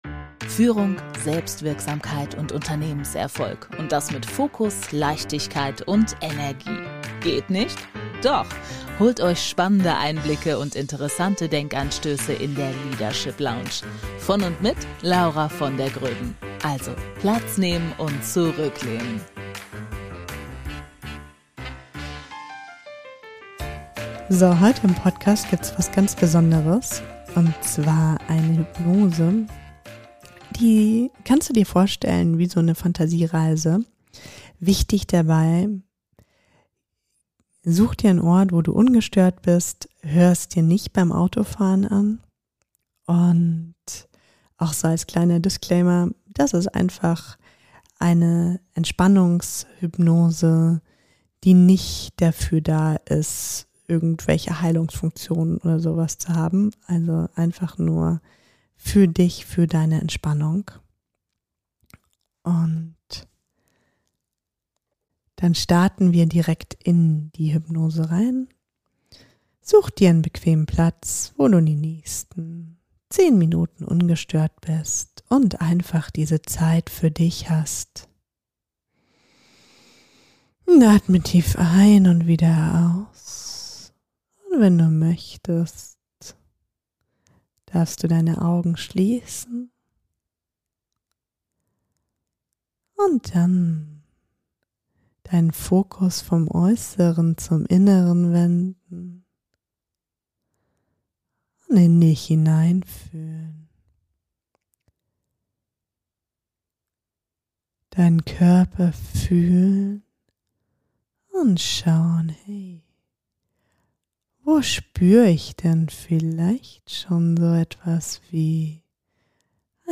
Dann ist diese Hypnose genau das Richtige für dich!